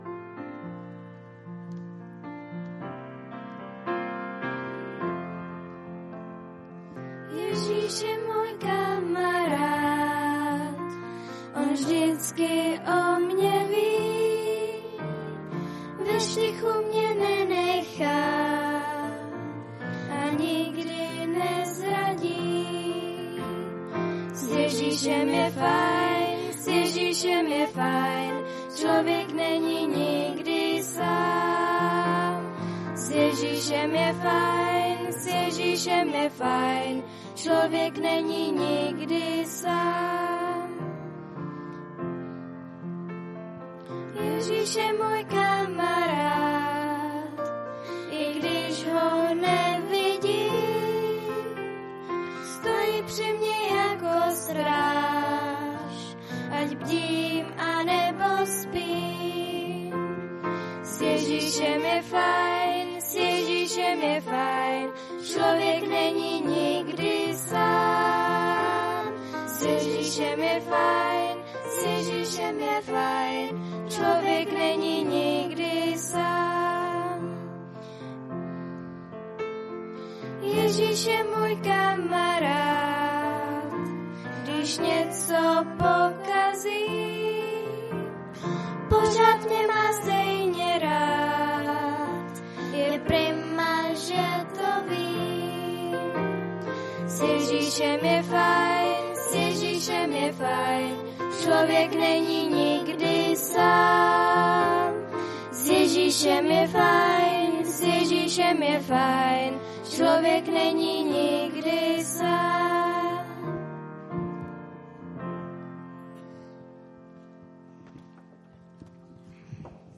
22.5.2016 v 19:53 do rubriky Kázání .